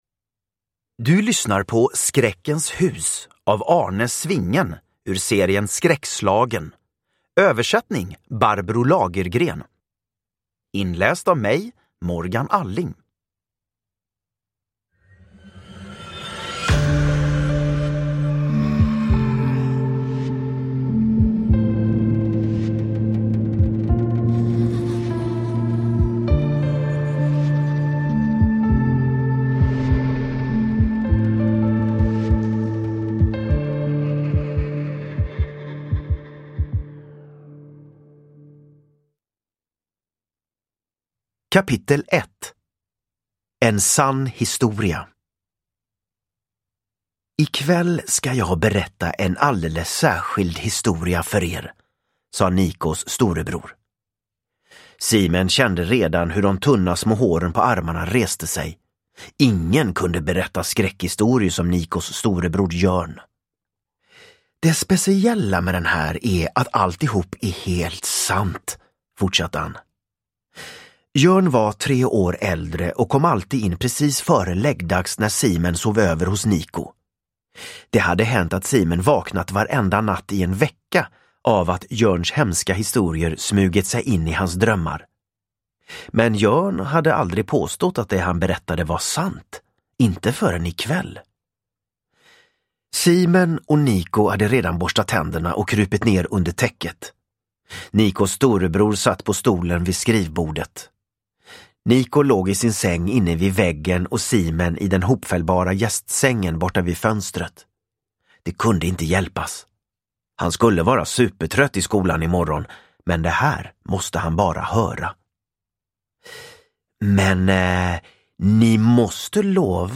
Skräckens hus – Ljudbok – Laddas ner
Uppläsare: Morgan Alling